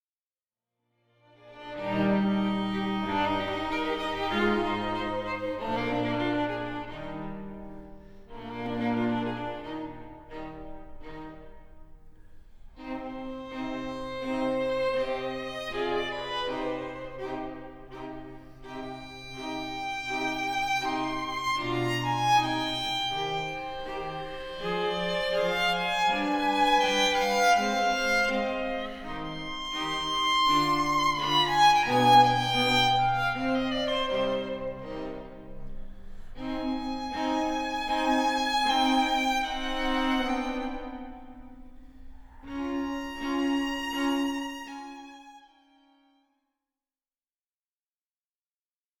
Grave e cantabile (1.29 EUR)